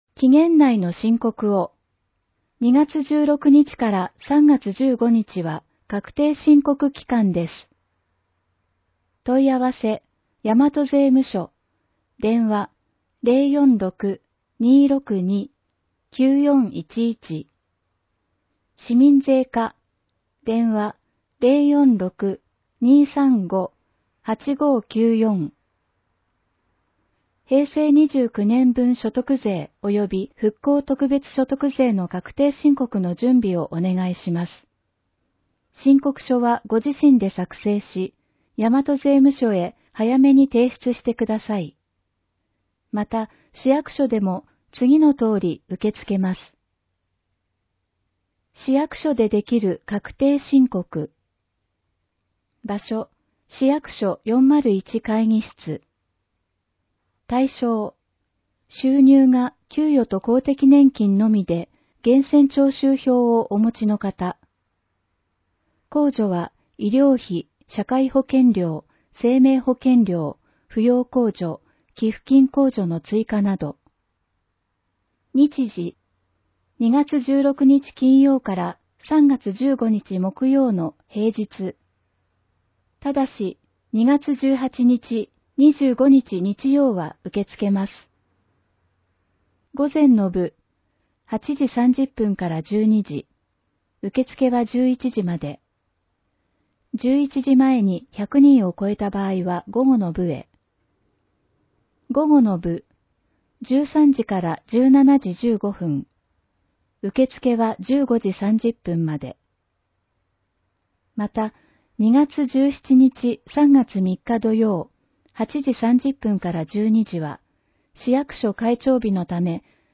広報えびな 平成30年1月15日号（電子ブック） （外部リンク） PDF・音声版 ※音声版は、音声訳ボランティア「矢ぐるまの会」の協力により、同会が視覚障がい者の方のために作成したものを登載しています。